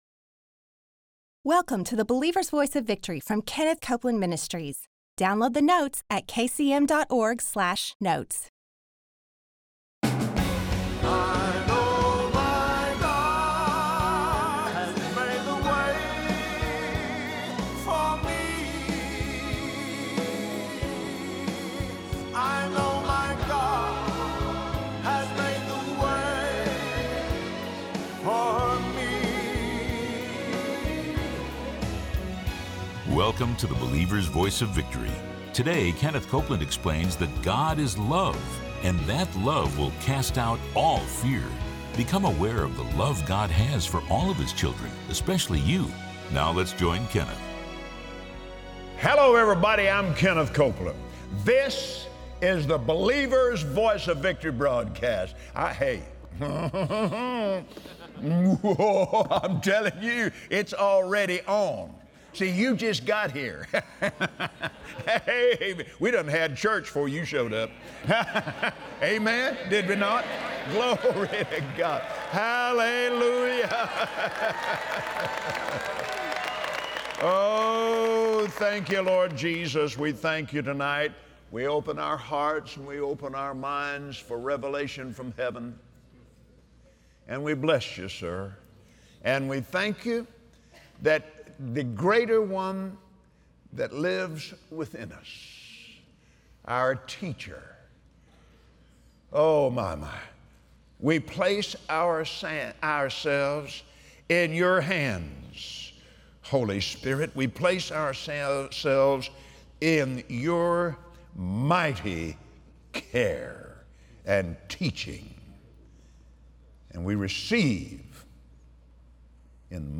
Believers Voice of Victory Audio Broadcast for Monday 06/05/2017 Many Christians would say they believe God loves them, but when times get tough, their words and actions say otherwise. Kenneth Copeland shares the importance of renewing your mind to God’s extraordinary love for you by faith on the Believer’s Voice of Victory.